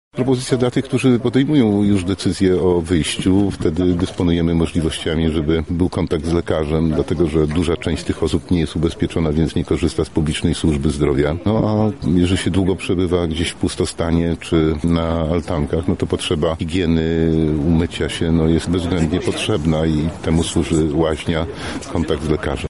„Widzę wielką potrzebę tego typu działań. „- mówi Biskup Pomocniczy Archidiecezji Lubelskiej Mieczysław Cisło: